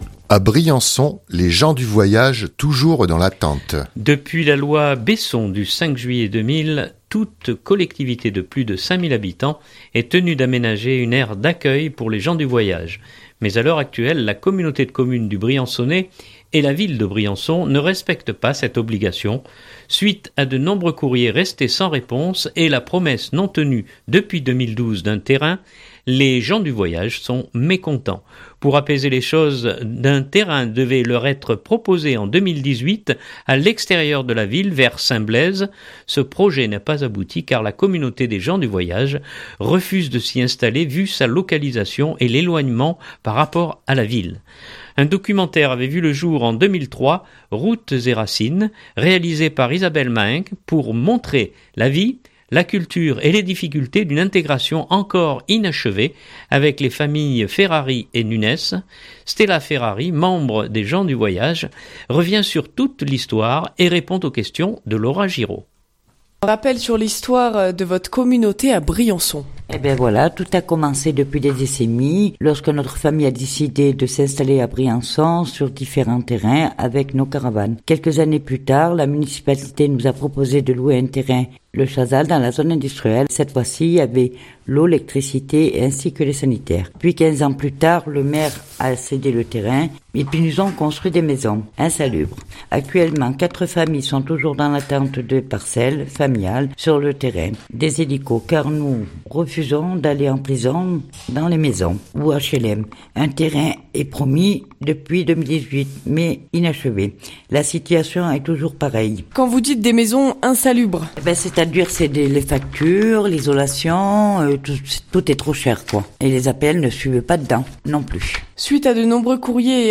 2019-01-15 reportage Briançon les gens du Voyage.mp3 (2.12 Mo)